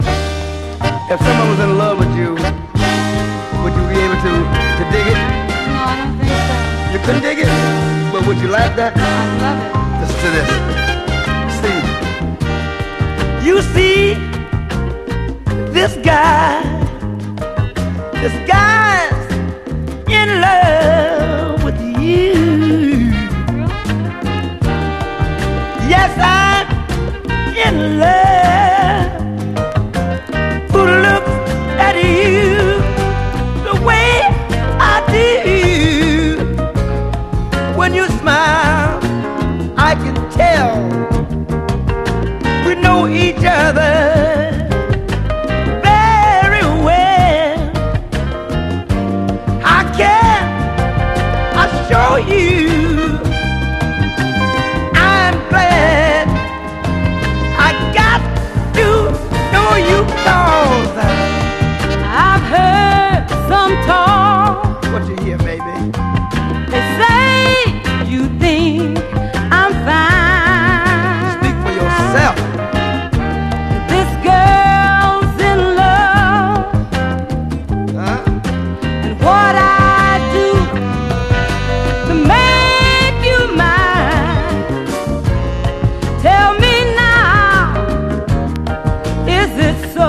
SOUL / RARE GROOVE/FUNK / 70'S SOUL
フィンガー・スナッピンなシャッフルビートにオルガンや心地よいホーンを配した